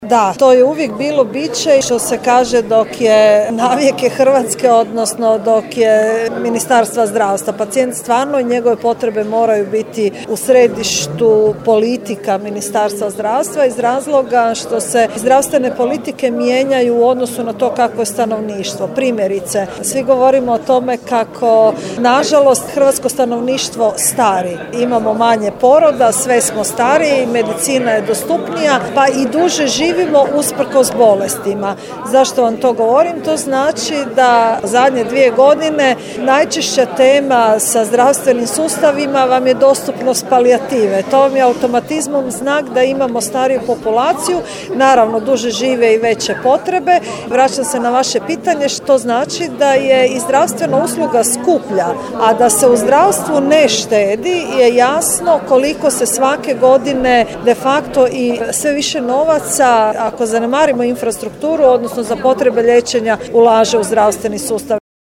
Razgovor je snimljen, recimo to tako, na marginama prošlotjednog sastanka o Razvojnom sporazumu za Sjever Hrvatske održanom u Daruvaru.